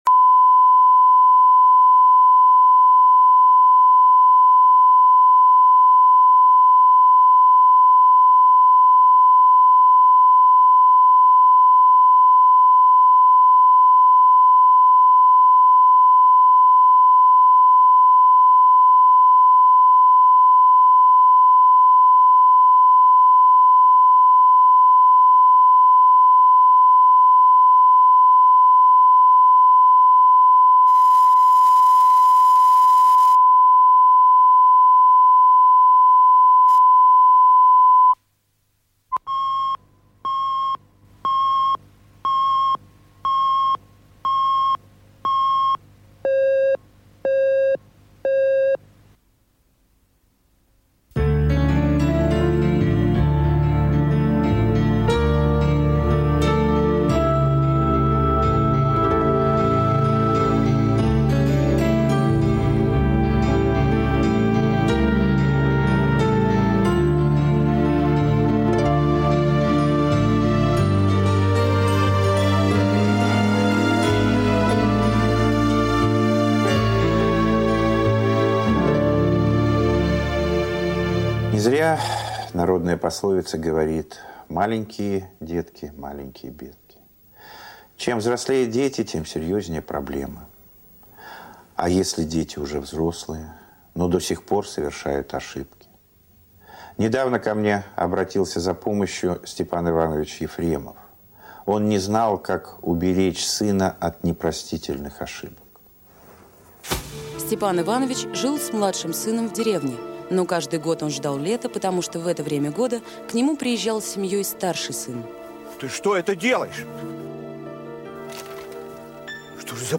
Аудиокнига Последняя просьба | Библиотека аудиокниг